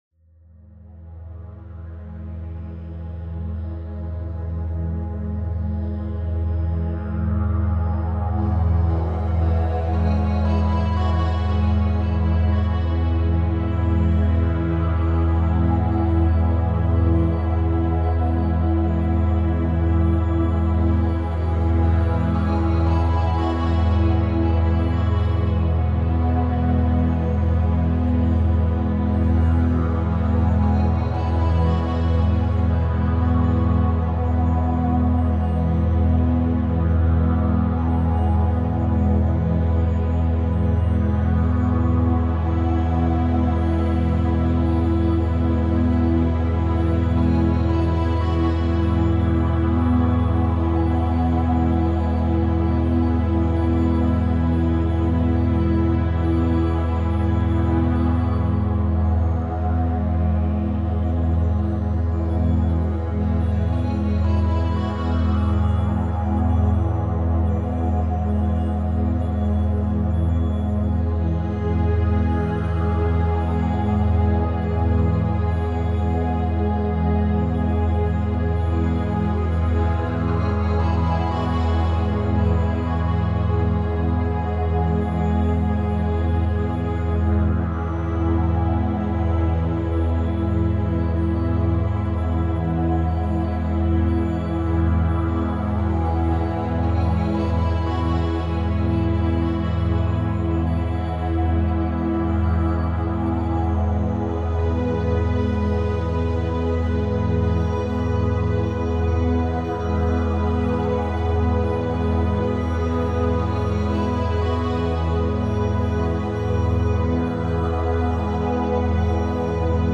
meditative, focusing track